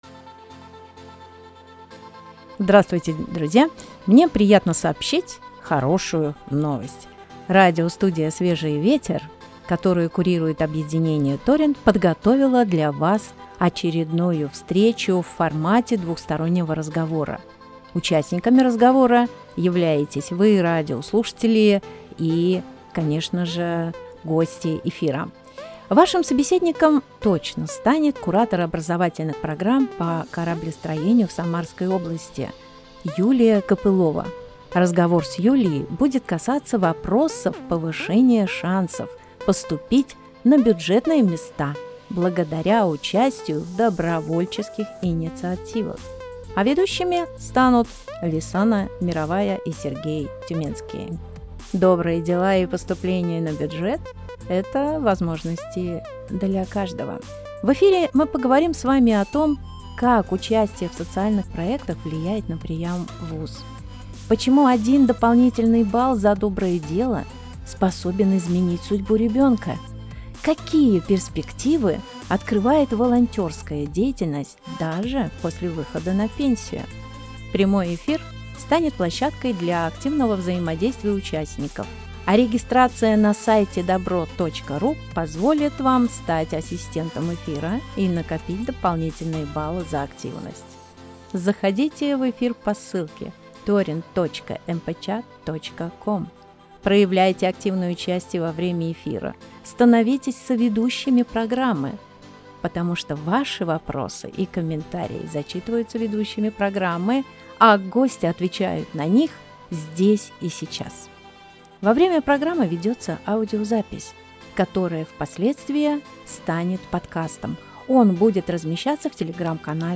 anons_vstrechi_v_studii_svezhi.mp3